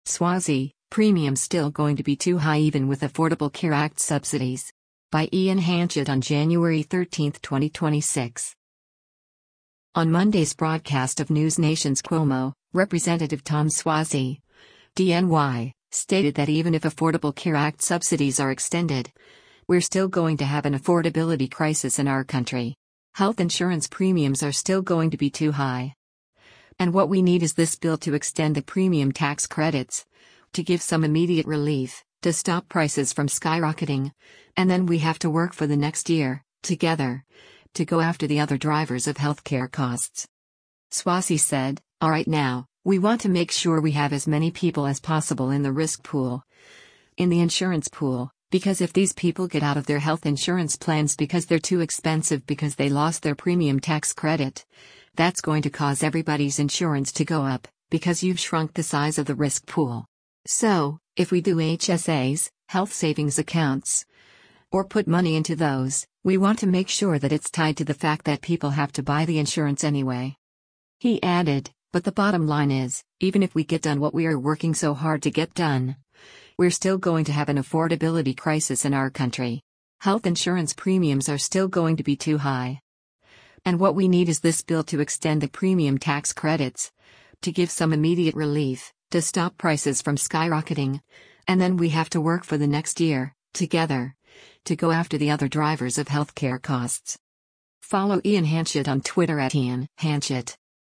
On Monday’s broadcast of NewsNation’s “Cuomo,” Rep. Tom Suozzi (D-NY) stated that even if Affordable Care Act subsidies are extended, “we’re still going to have an affordability crisis in our country.